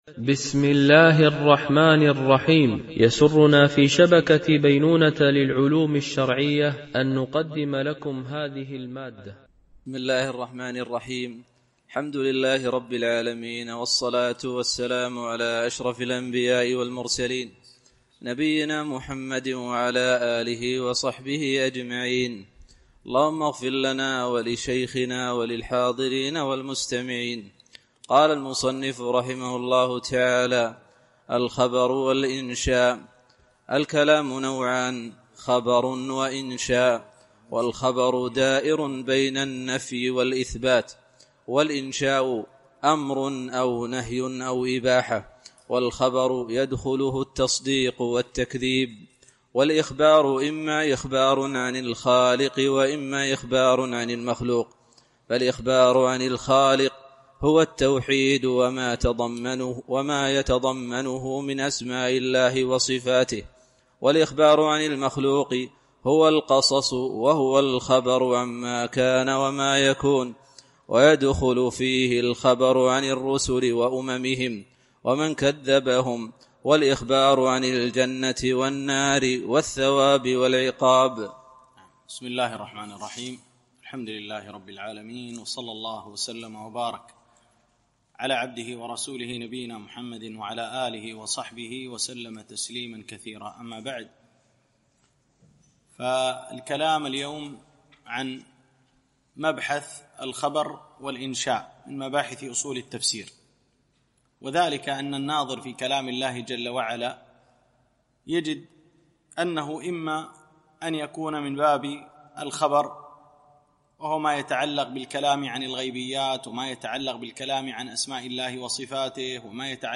دورة علمية شرعية
بمسجد عائشة أم المؤمنين - دبي (القوز 4)